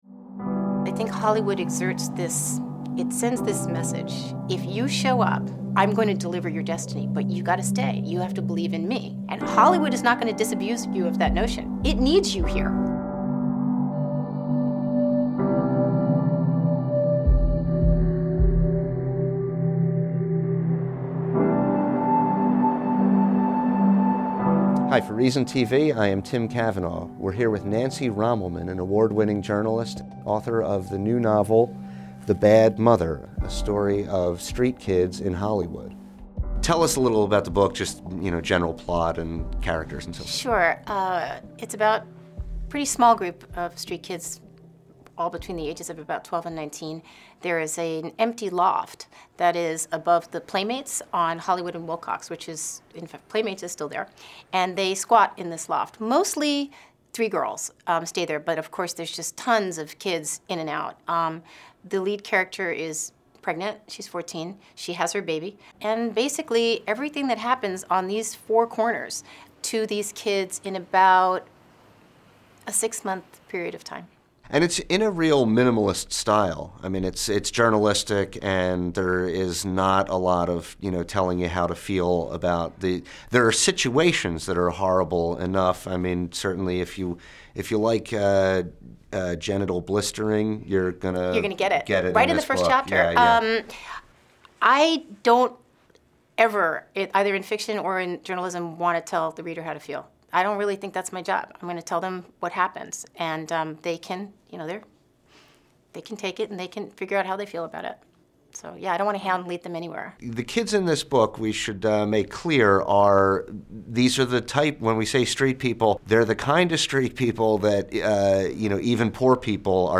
to discuss the book